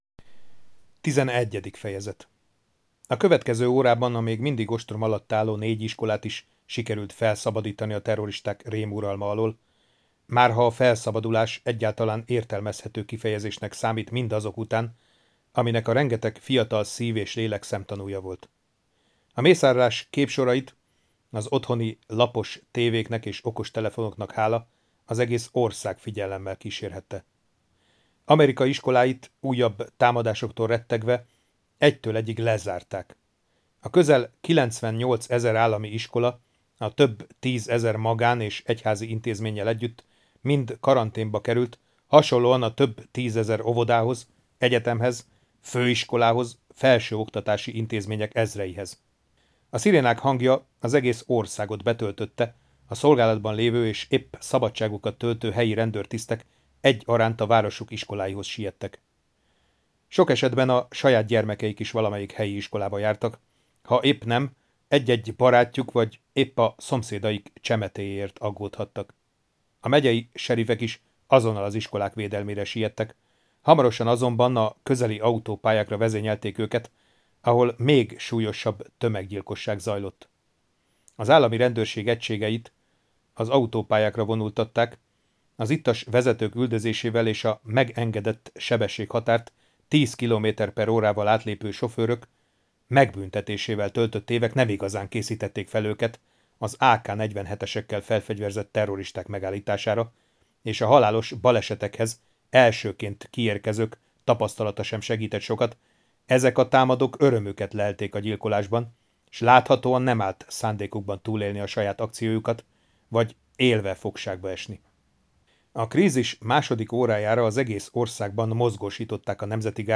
A harag napja HANGOSKÖNYV Felolvassa